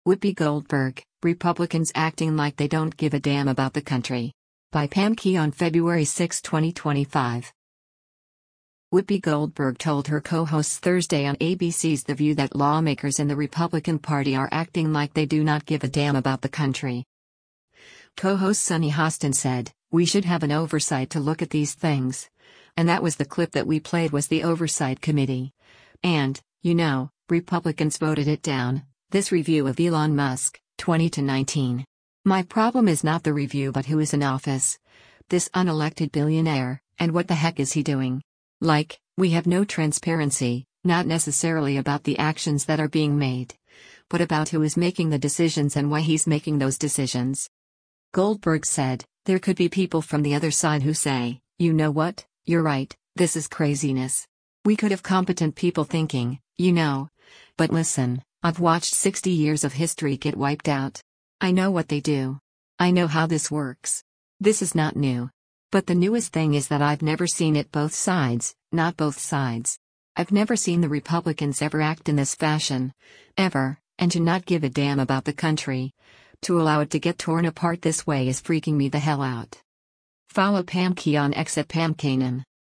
Whoopi Goldberg told her co-hosts Thursday on ABC’s “The View” that lawmakers in the Republican Party are acting like they do not “give a damn about the country.”